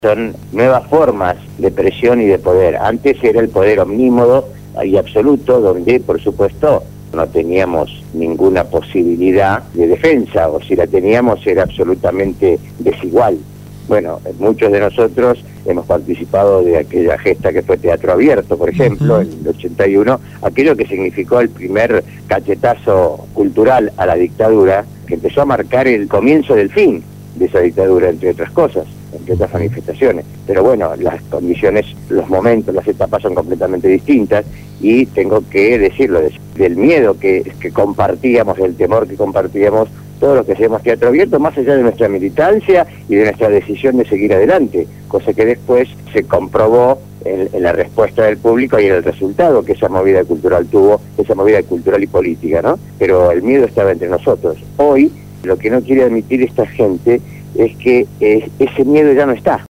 La  entrevista fue realizada en el programa Punto de Partida de Radio Gráfica FM 89.3